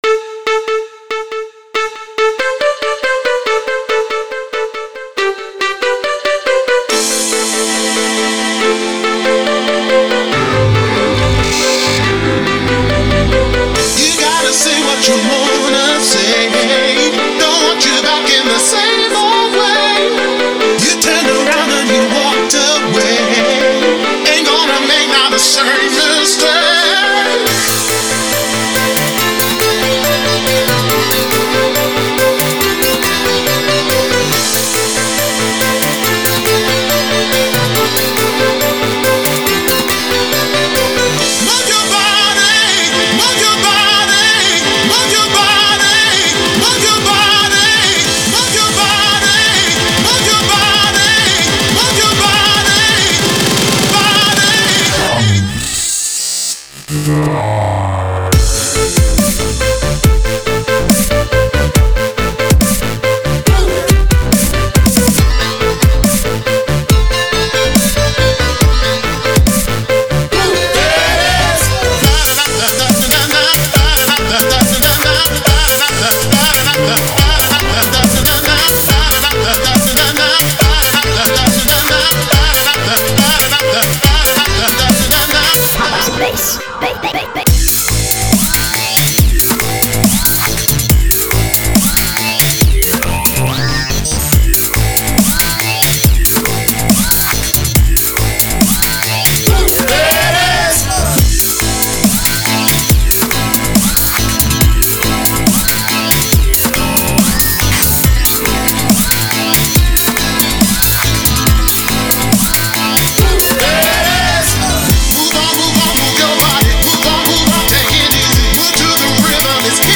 Ремикс
Сэмплы: Свои + вокал + FX